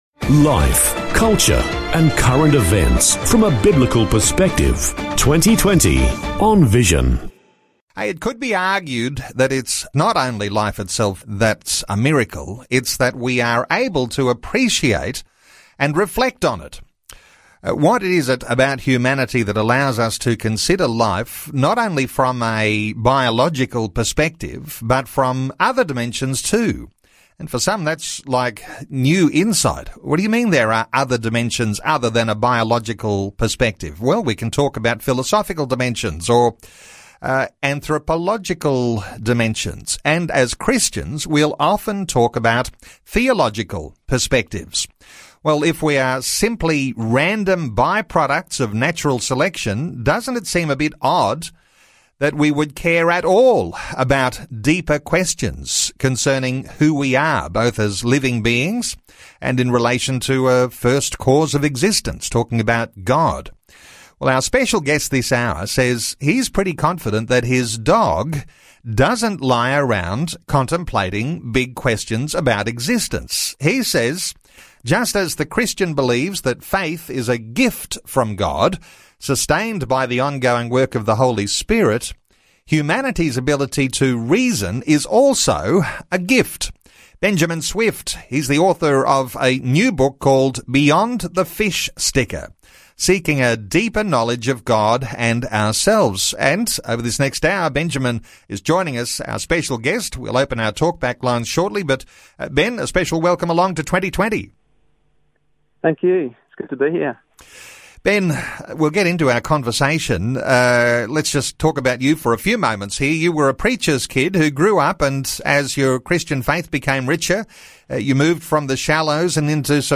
Vision Radio Interview
What a great but challenging experience as with anything done live.
vision-radio-interview.mp3